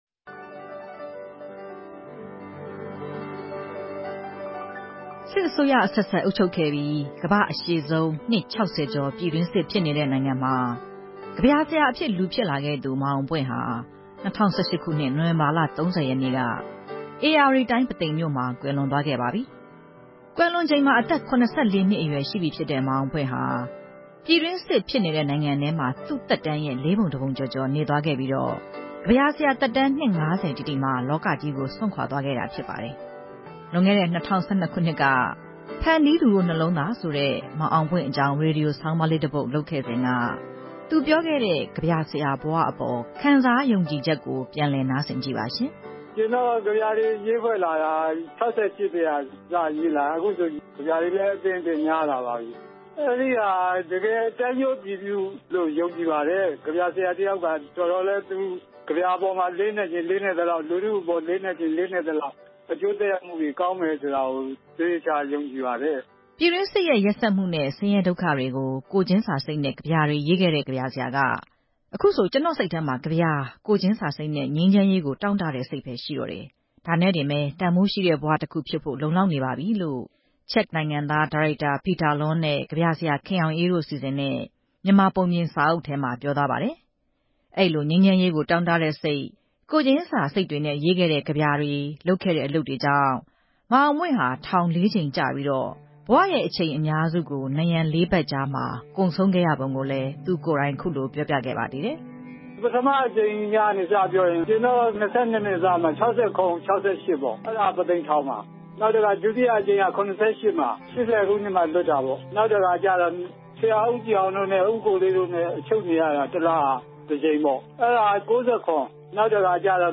သူကိုယ်တိုင် ပြောပြရွတ်ဆိုခဲ့တာတွေကို